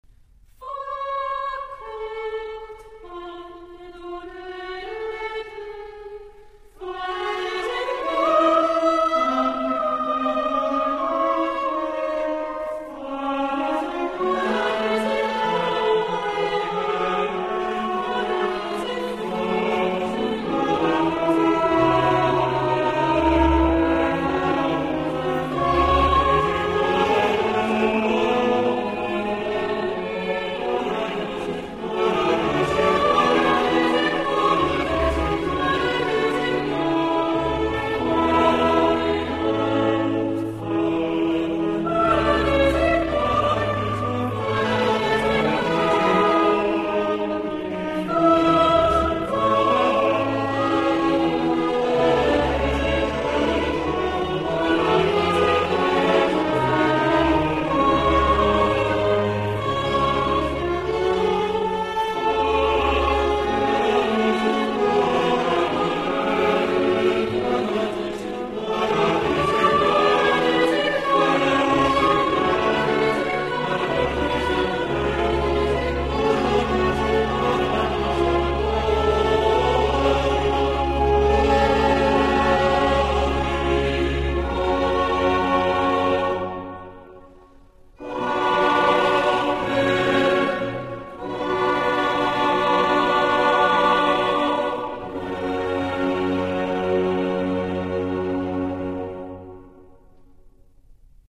Coro della radio Svizzera - Diego Fasolis & Aura Musicale Budapest - René Clemencic
ATTENTION ! Dans cet enregistrement, on utilise le "diapason baroque" (La415).
On l'entend donc environ un demi-ton en dessous du diapason actuel (La440) que l'on emploiera et qui est celui des fichiers midi et virtual voice.